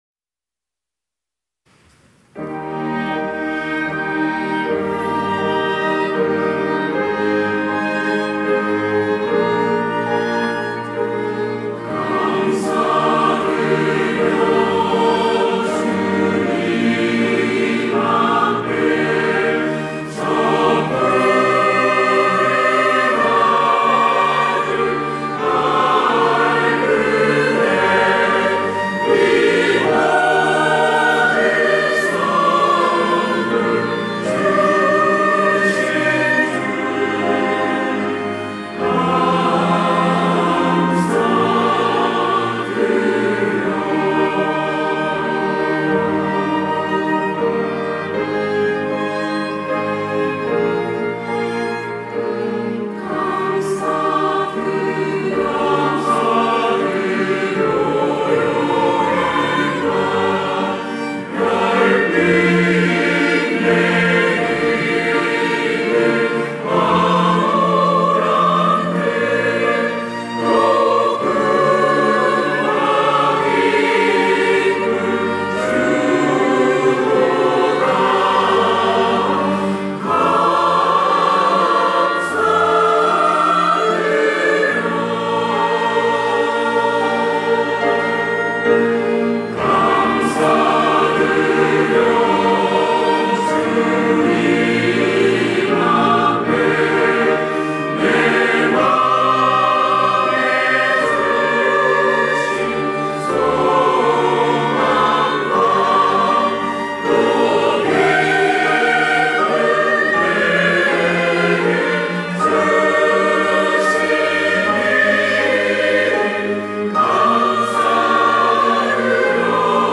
호산나(주일3부) - 감사드려
찬양대 호산나